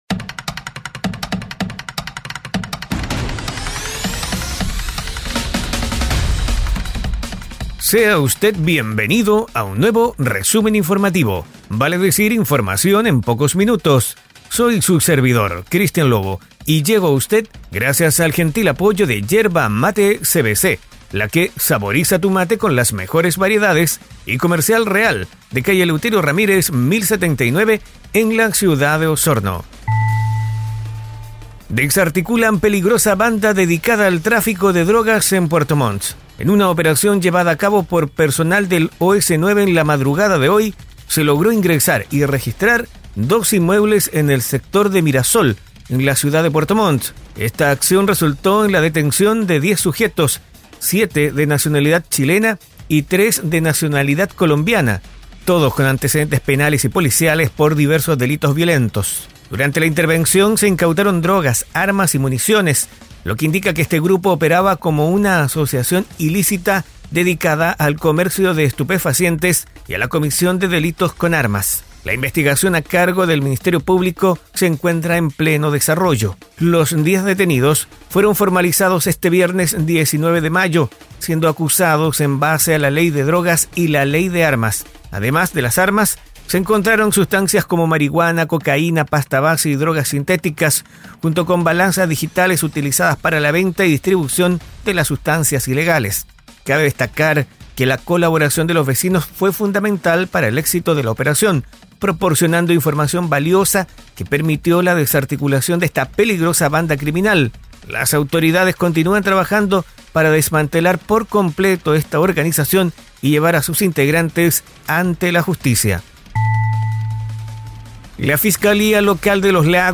🐺Resumen informativo es un audio podcast con una decena de informaciones en pocos minutos, enfocadas en la Región de Los Lagos